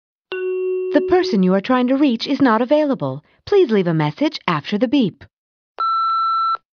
Nokia Laughing Sound Effect Free Download